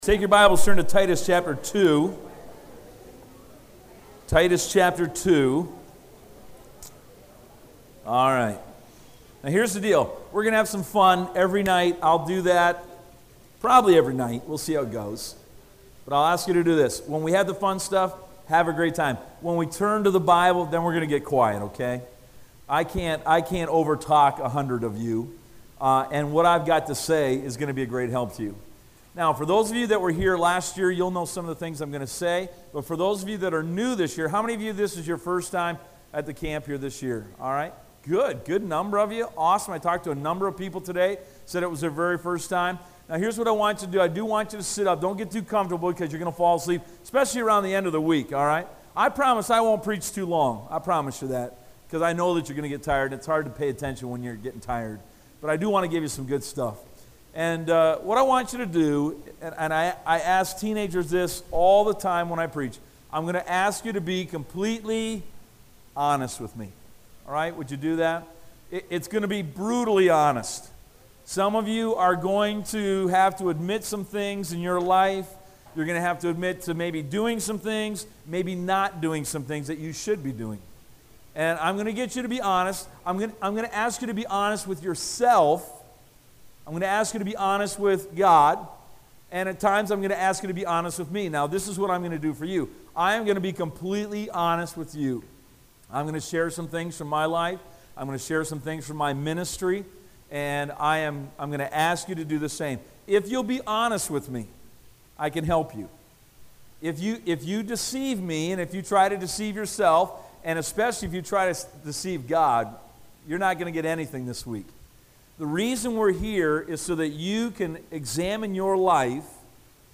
Listen to Message
Service Type: Teen Camp